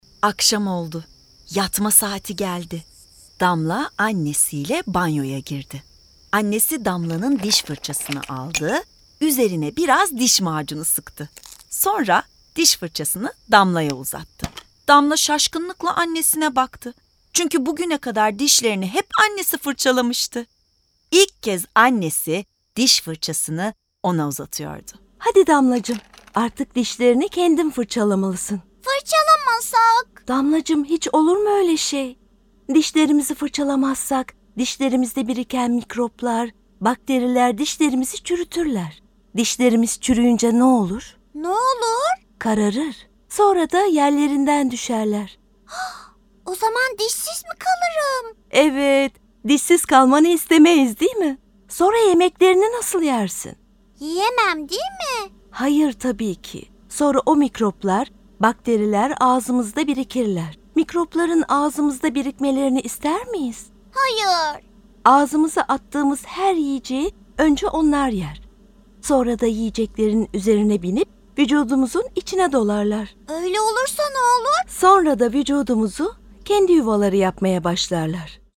Damla Diş Fırçalıyor Tiyatrosu